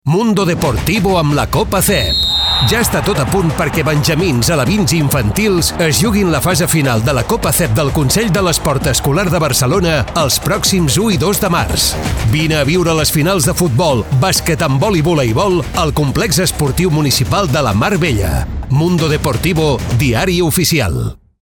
Falca de ràdio sobre les Fases Finals Mundo Deportivo 2025, emesa a RAC1 RAC105.